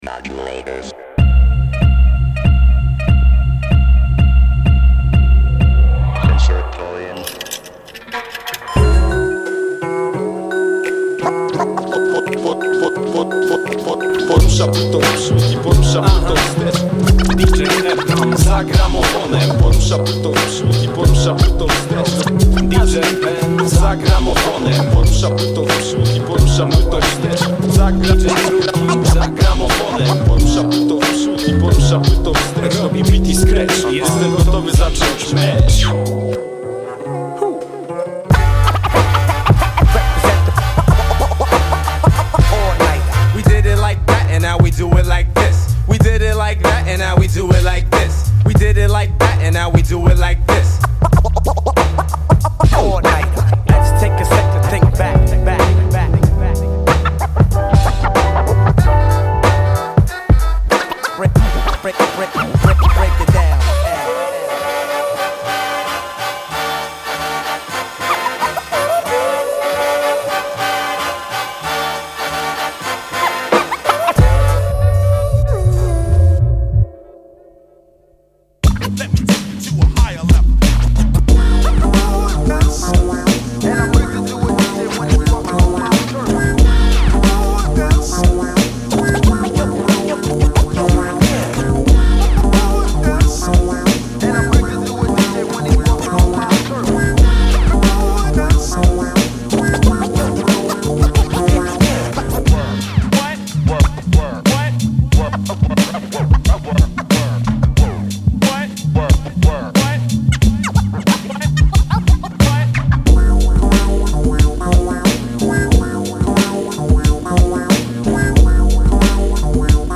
15 tracks of beats, samples & scratches